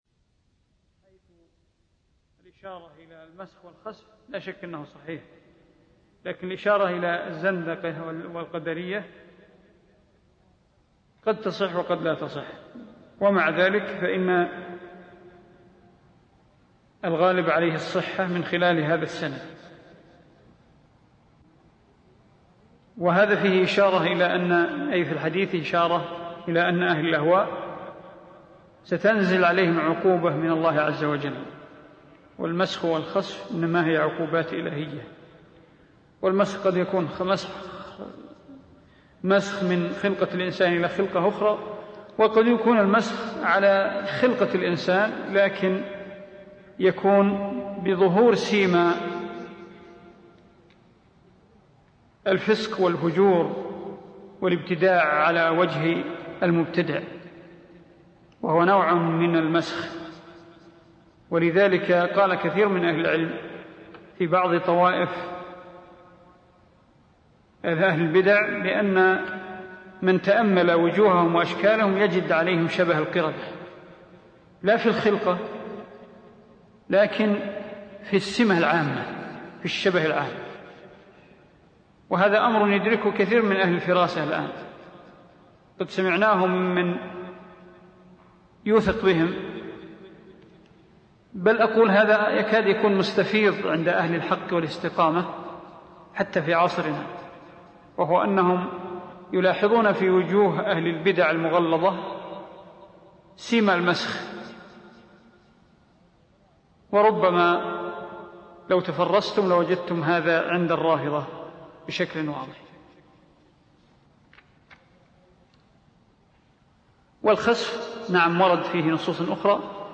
عنوان المادة الدرس (24) شرح أصول إعتقاد أهل السنة والجماعة تاريخ التحميل الأحد 1 يناير 2023 مـ حجم المادة 42.20 ميجا بايت عدد الزيارات 201 زيارة عدد مرات الحفظ 118 مرة إستماع المادة حفظ المادة اضف تعليقك أرسل لصديق